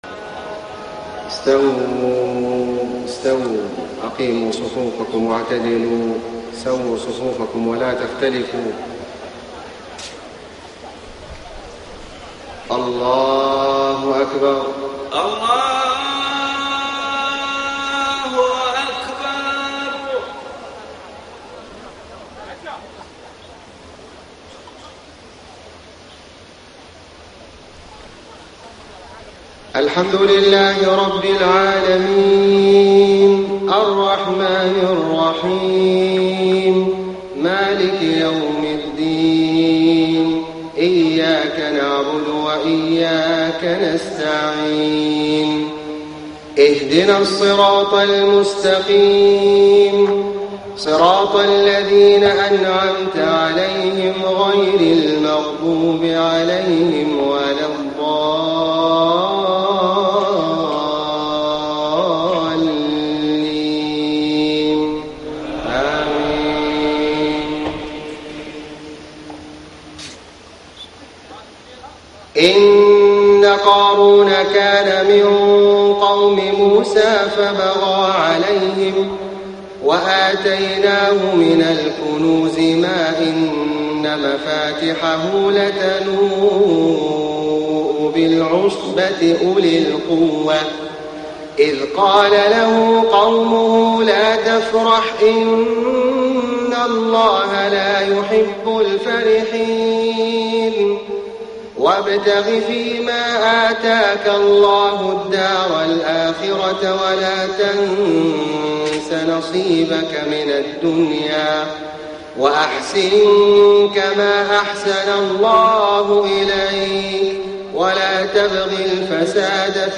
صلاة العشاء 6-5-1434 من سورة القصص > 1434 🕋 > الفروض - تلاوات الحرمين